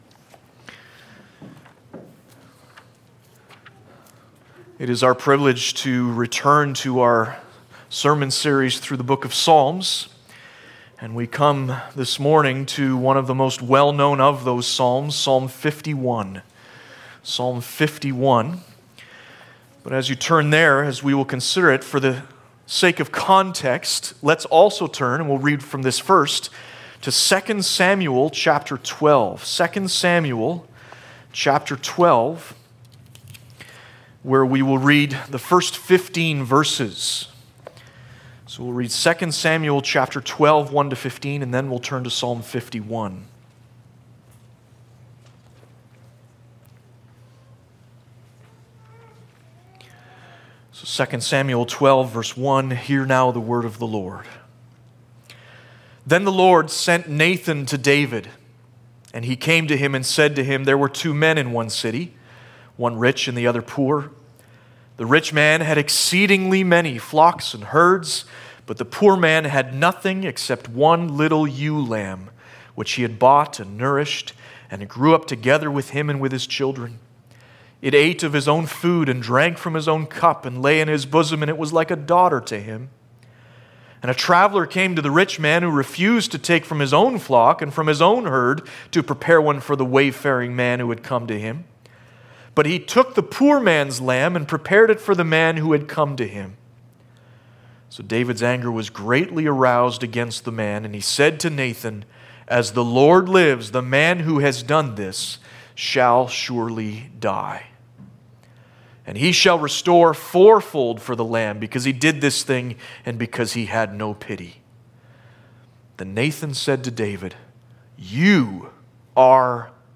Psalm 51 Service Type: Sunday Morning Bible Text